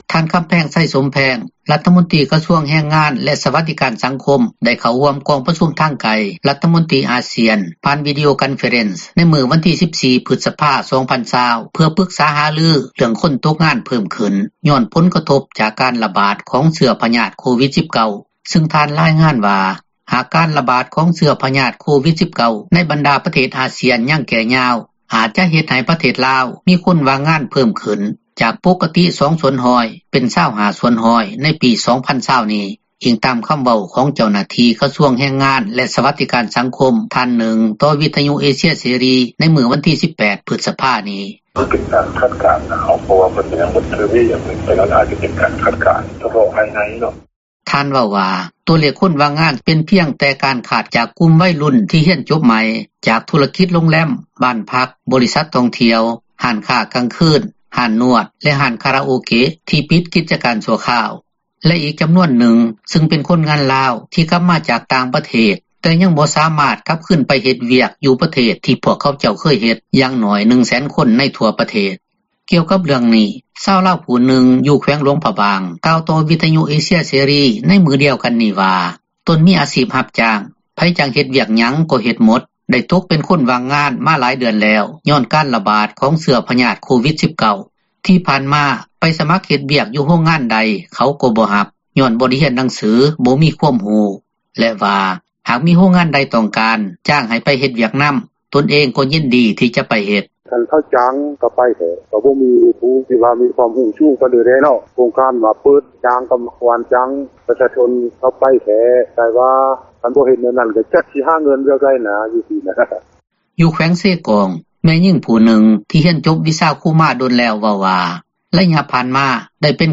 ກ່ຽວກັບເຣຶ່ອງນີ້ ຊາວລາວຜູ້ນຶ່ງ ຢູ່ແຂວງຫລວງພຣະບາງ ກ່າວຕໍ່ວິທຍຸເອເຊັຍເສຣີ ໃນມື້ດຽວກັນນີ້ວ່າ ຕົນມີອາຊີບຮັບຈ້າງ, ໃຜຈ້າງເຮັດ ວຽກຫຍັງ ກໍເຮັດໝົດ, ໄດ້ຕົກເປັນຄົນວ່າງງານ ມາຫລາຍເດືອນແລ້ວ ຍ້ອນການຣະບາດຂອງເຊື້ອ ພຍາດໂຄວິດ-19.
ຢູ່ແຂວງເຊກອງ ແມ່ຍິງຜູ້ນຶ່ງ ທີ່ຮຽນຈົບວິຊາຄຣູ ເວົ້າວ່າ, ໄລຍະຜ່ານມາ ເປັນຄຣູອາສາສະໝັກ ສອນເດັກນ້ອຍມາຫລາຍປີ ແຕ່ກໍບໍ່ຖືກ ສັບຊ້ອນເຂົ້າເປັນພະນັກງານຄຣູຈັກເທື່ອ ແລ້ວກໍອອກມາຊອກວຽກ ເຮັດ ແຕ່ຍ້ອນຄອບຄົວທຸກຍາກ ຢູ່ຫ່າງໄກຈາກຕົວເມືອງ ຈຶ່ງມີ ຄວາມຫຍູ້ງຍາກ ໃນການອອກໄປຊອກວຽກ ເຮັດງານທໍາ.